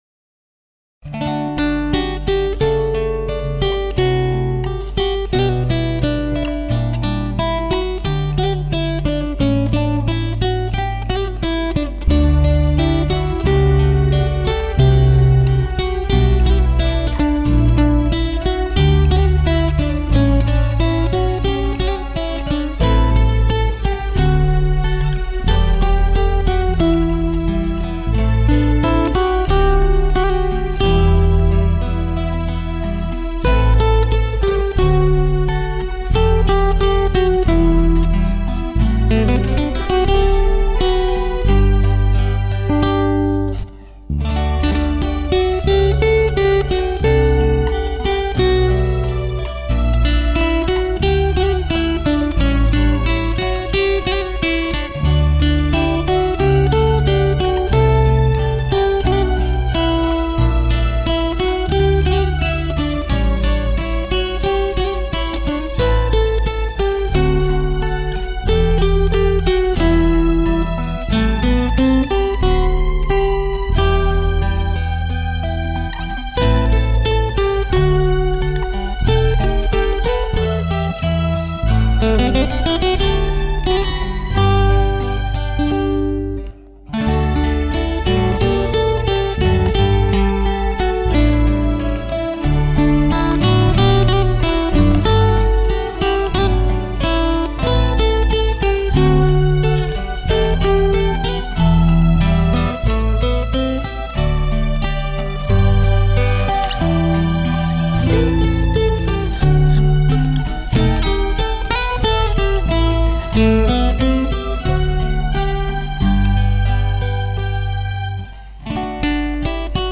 Менуэт    (MP3, stereo128 кбит/с, 2:55)
02_minuet.mp3